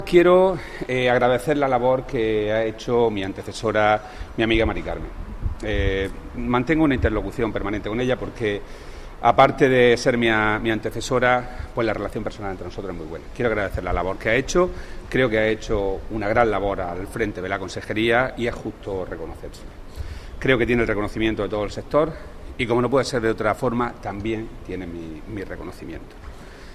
Declaraciones de Rodrigo Sánchez agradeciendo la labor de su antecesora en el acrgo, Carmen Ortiz